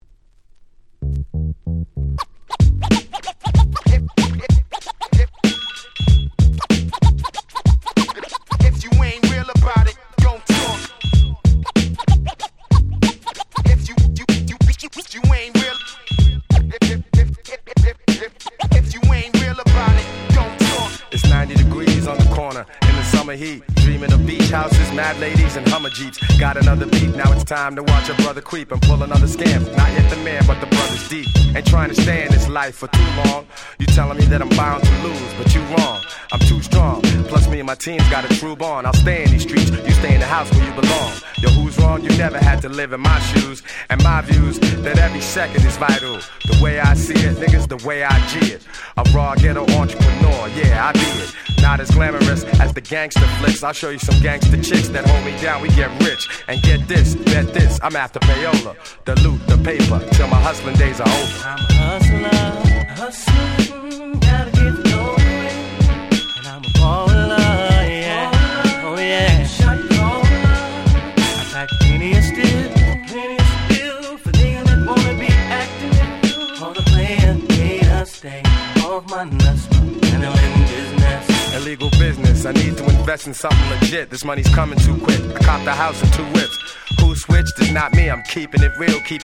【Media】Vinyl 12'' Single
Very Nice 00's R&B / Hip Hop Soul !!